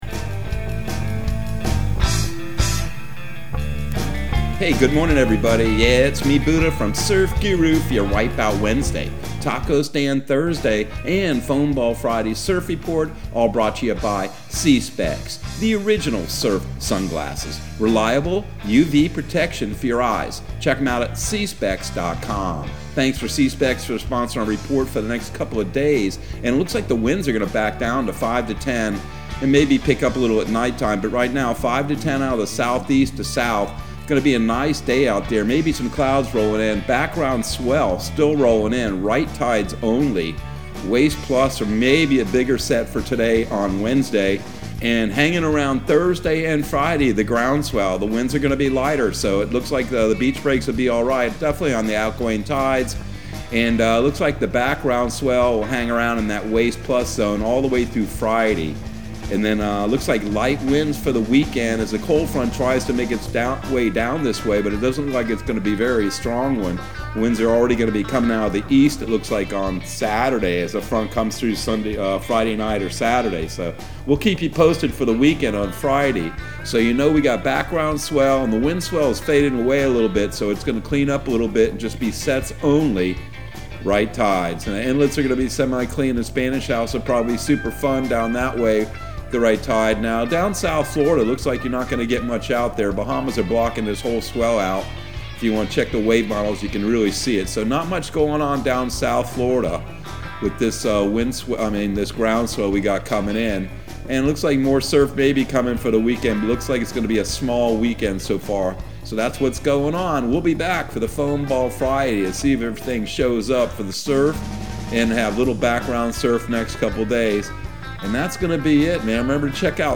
Surf Guru Surf Report and Forecast 02/23/2022 Audio surf report and surf forecast on February 23 for Central Florida and the Southeast.